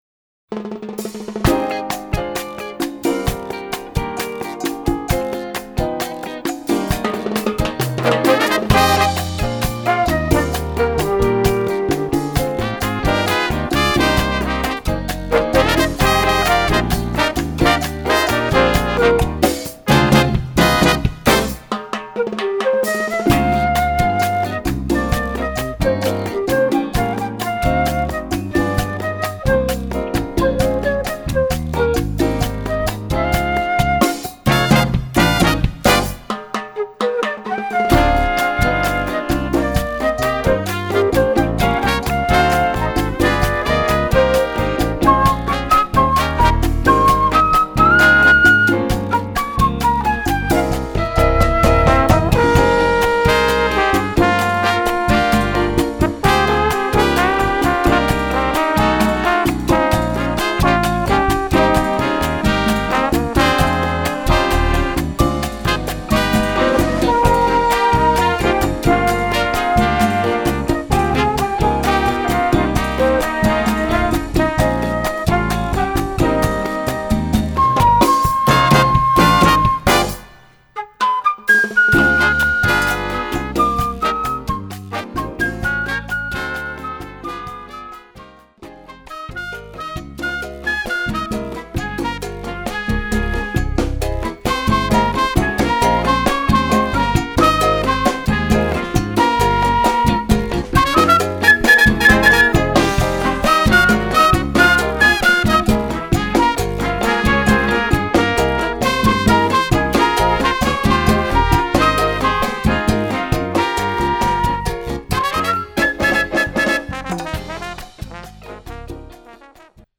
Category: combo (nonet)
Style: mambo
Solos: piano, flute